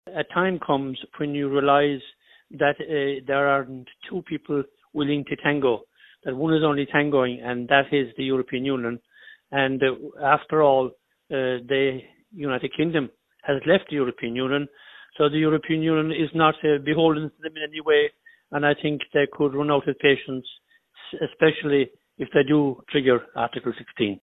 MEP Sean Kelly says Brussels would have to react if Article 16 of the protocol is triggered: